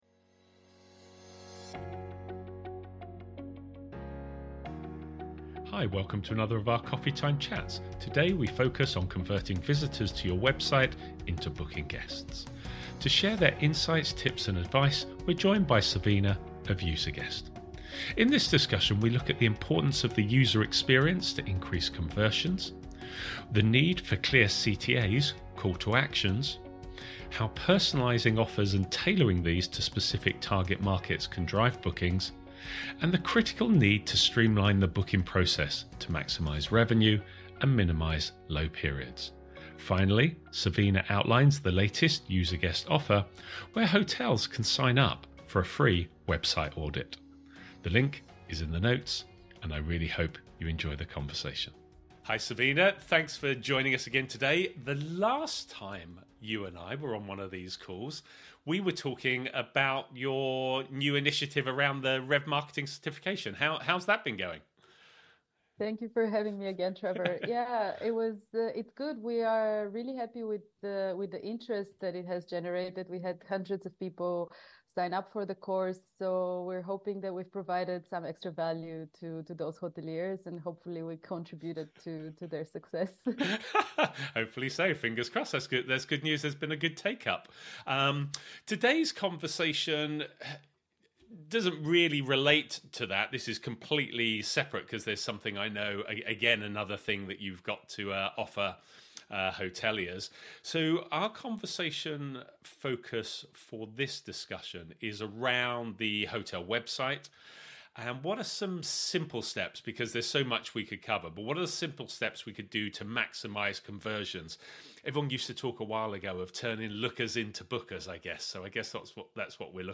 Hi, welcome to another of our Coffee Time Chats
Really hope you enjoy the conversation